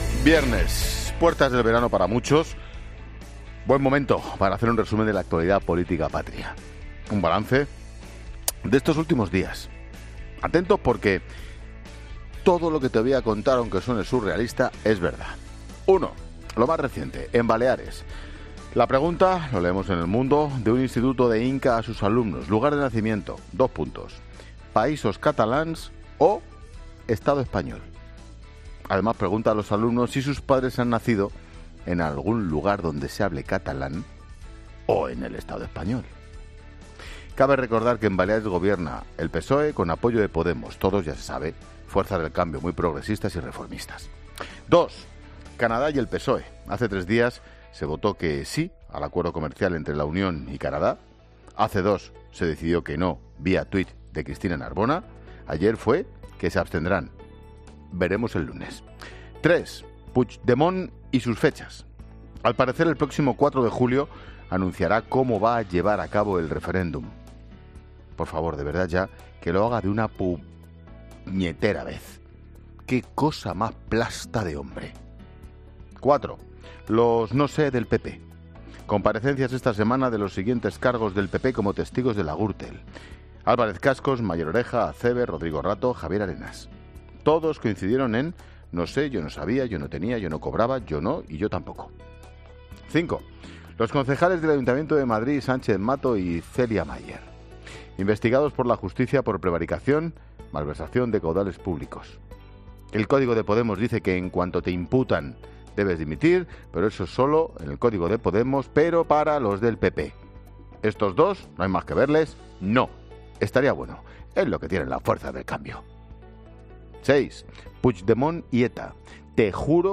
AUDIO: Monólogo de Ángel Expósito a las 16h. con las noticias polìticas que nos ha dejado la semana.